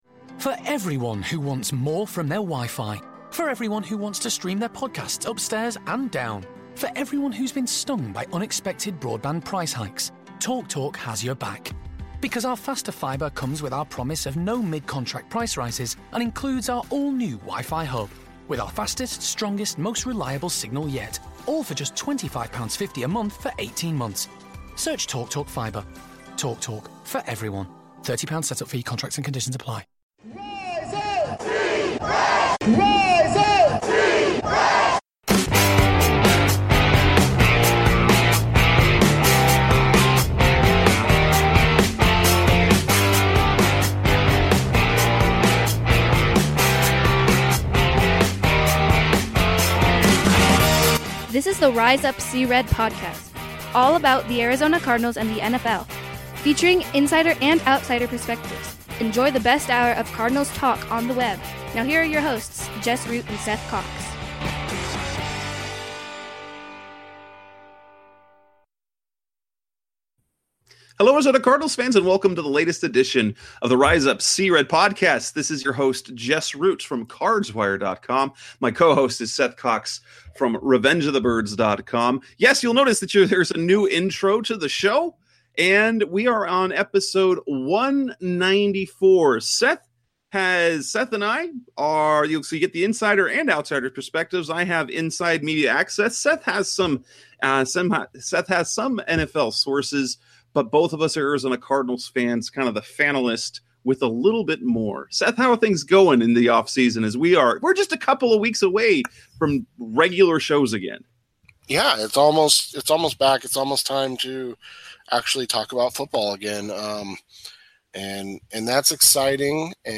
(You will also note new intro and outro audio).